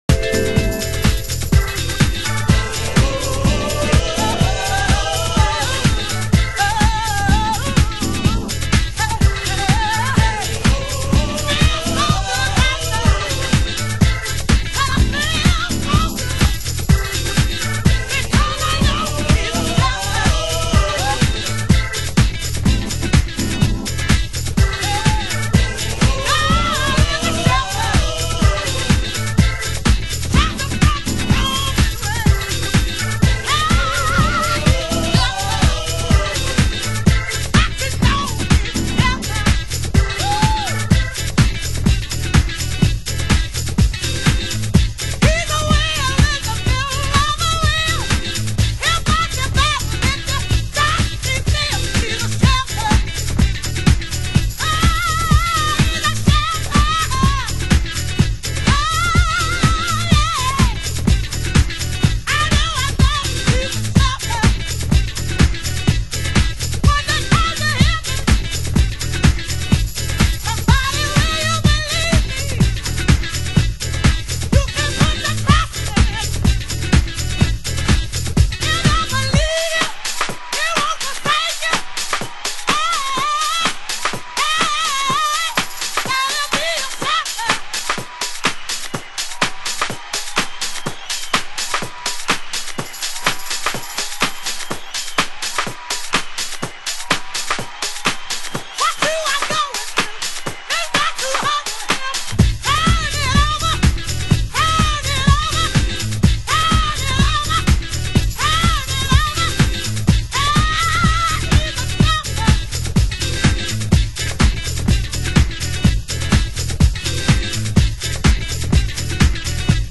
(Vocal Mix)
盤質：小傷有/少しチリパチノイズ有